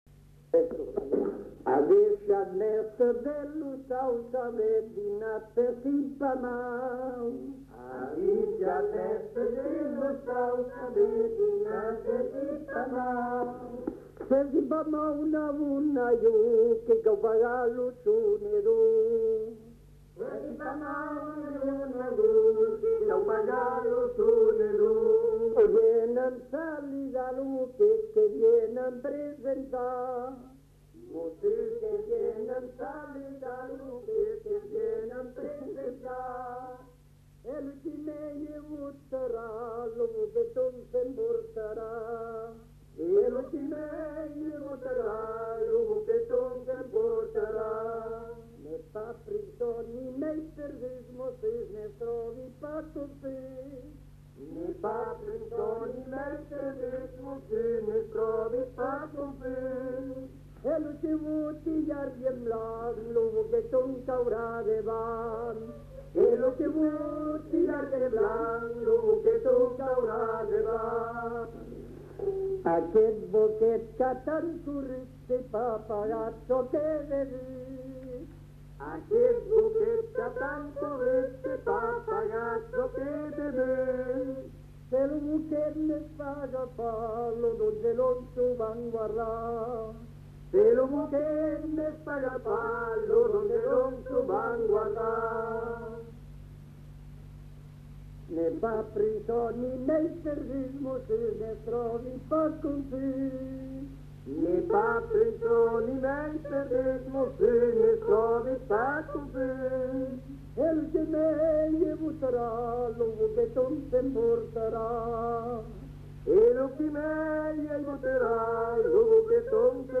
[Brocas. Groupe folklorique] (interprète)
Aire culturelle : Marsan
Genre : chant
Type de voix : voix mixtes
Production du son : chanté